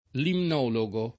limnologo [ limn 0 lo g o ] s. m.; pl. ‑gi